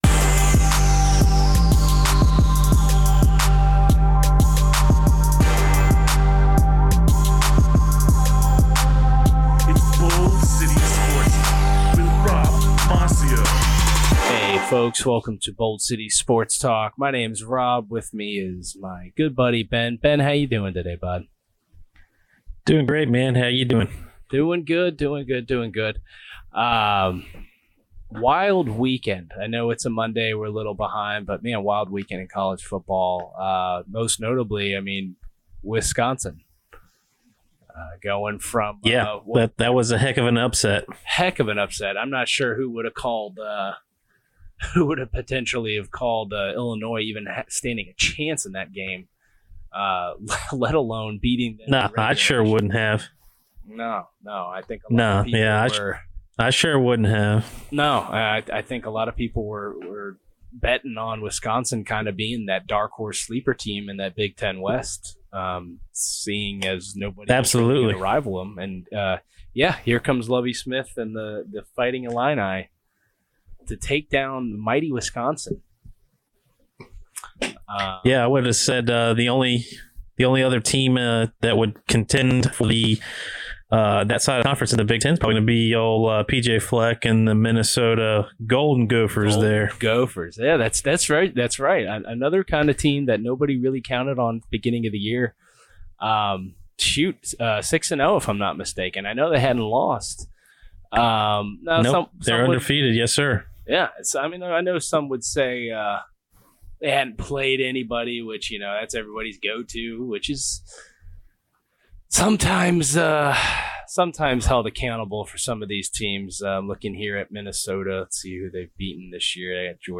talk over Cleanfeed